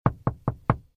StukiPoSteklu.ogg